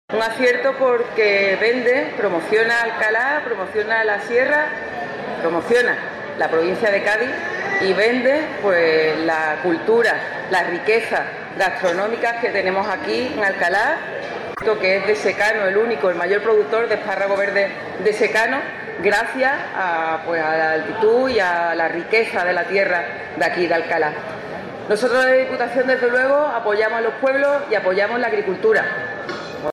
Audio de la presidenta de Diputación
La presidenta Almudena Martínez interviene en la inauguración y defiende el valor del sector primario
Presidenta-de-Diputacion_feria-esparrago-Alcala-del-Valle.mp3